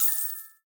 coin.ogg